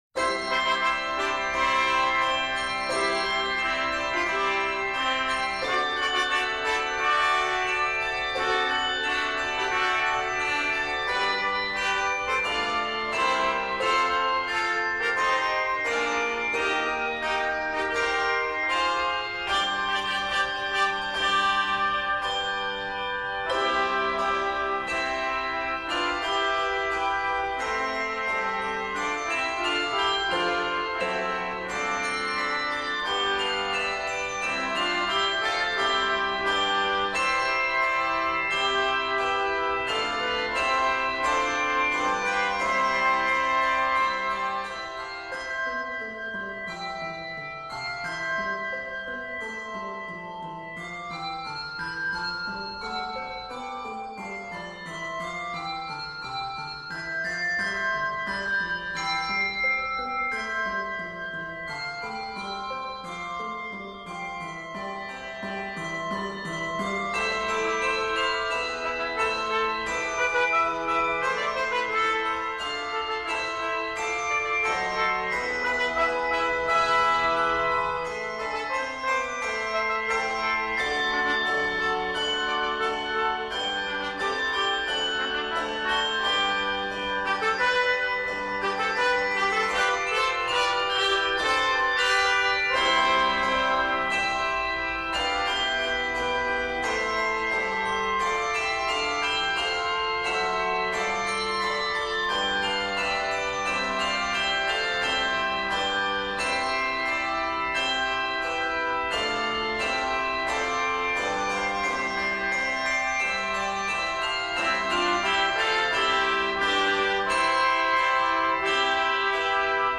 Set in C Major, this arrangement is 53 measures.